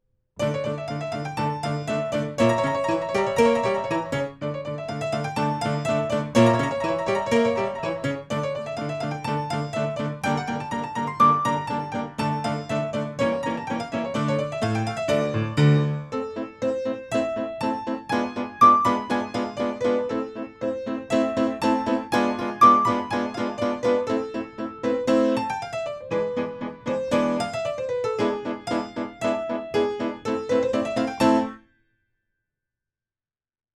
Notes: for piano
Allegro-Hurry–C.